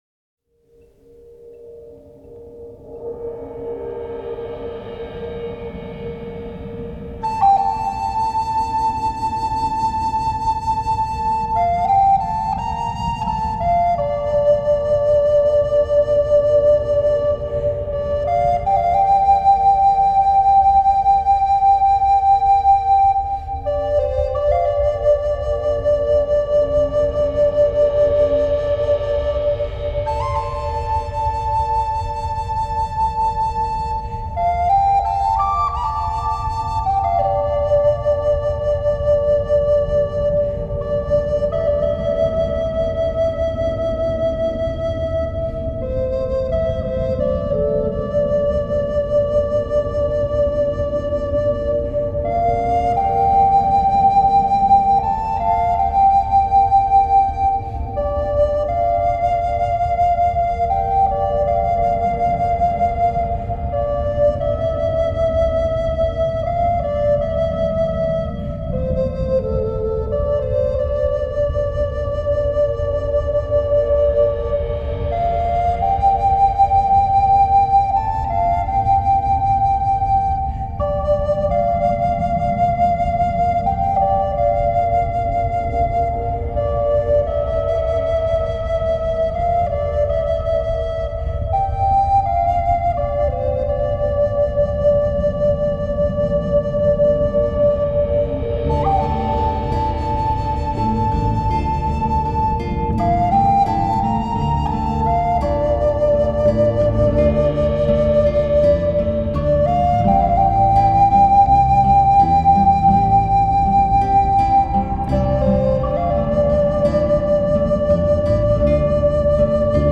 Genre: Native American Flute
此版本笛子结合多种乐器，鼓，键盘和大自然的声音来提供一个独特的音乐旅程的美国原住民族长笛。